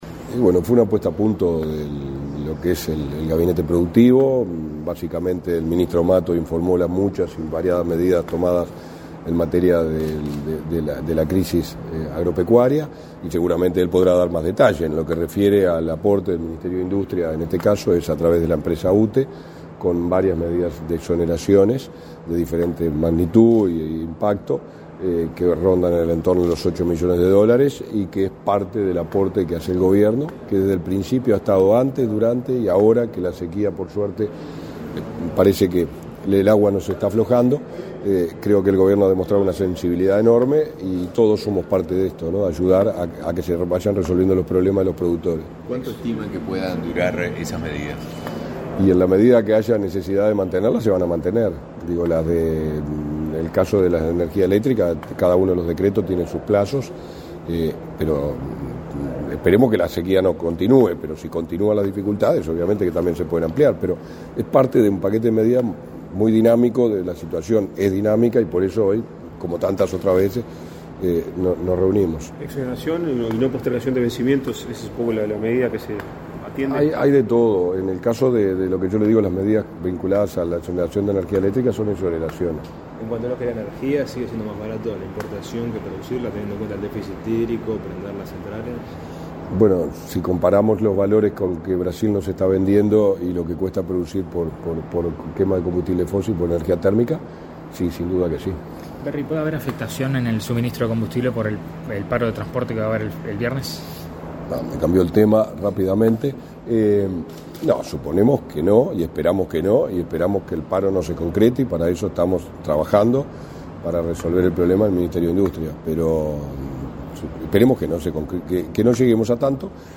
Declaraciones a la prensa del subsecretario de Industria, Energía y Minería, Walter Verri
Declaraciones a la prensa del subsecretario de Industria, Energía y Minería, Walter Verri 22/03/2023 Compartir Facebook X Copiar enlace WhatsApp LinkedIn Tras participar en la reunión del Gabinete Productivo con el presidente de la República, Luis Lacalle Pou, este 22 de marzo, el subsecretario Walter Verri realizó declaraciones a la prensa.